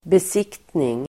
Uttal: [bes'ik:tning]